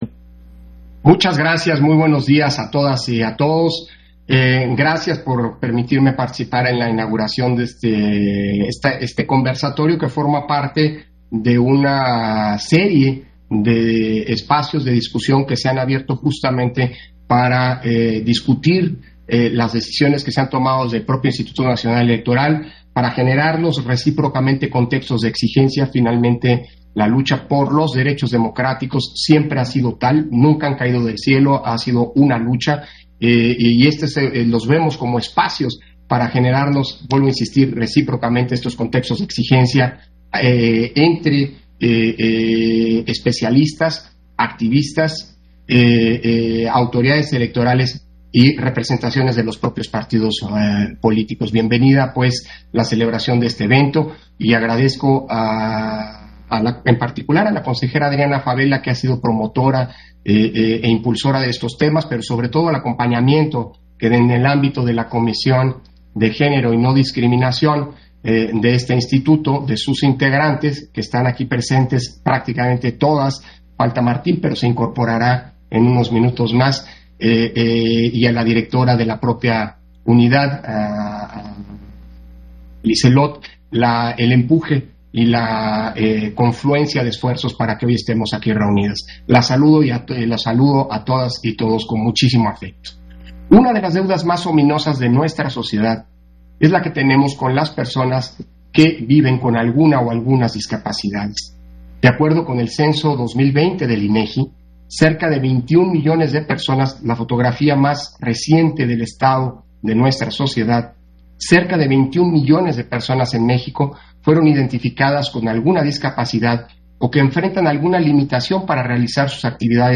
Intervención de Lorenzo Córdova, en la inauguración del Conversatorio de las Acciones Afirmativas a la Acción